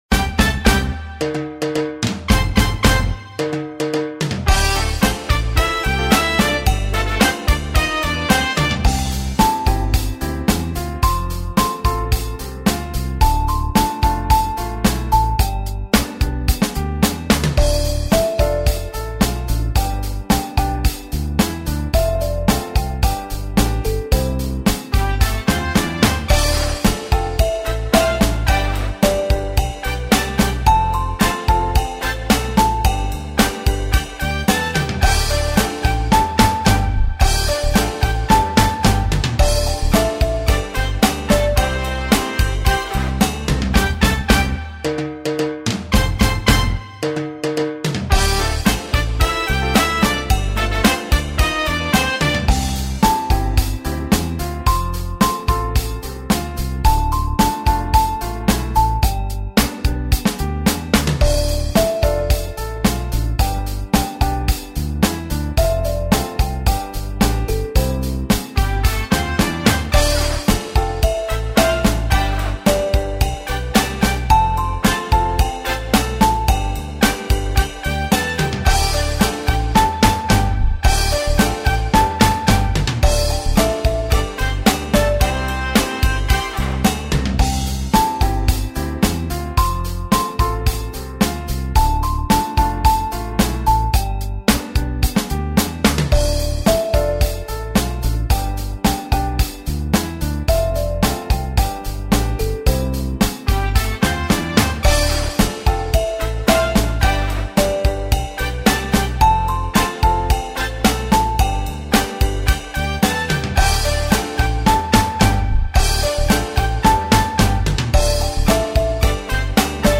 蝦公搞水(純伴奏版) | 新北市客家文化典藏資料庫